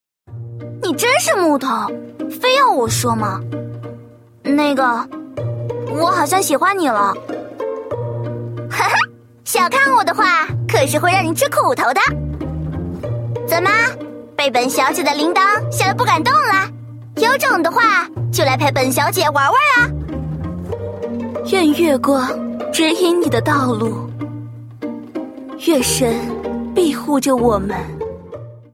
配音样音（女）-绯雨音乐
2nv47-ZY-shaonv.mp3